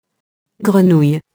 grenouille [grənuj]